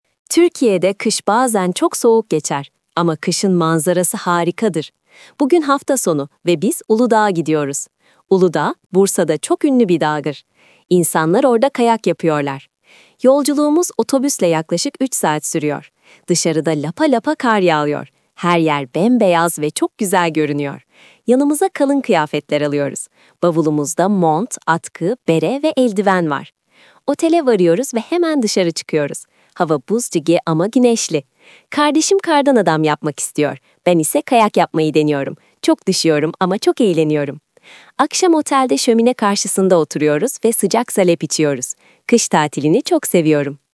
Okuma Parçası (Lesetext)